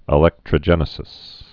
(ĭ-lĕktrə-jĕnĭ-sĭs)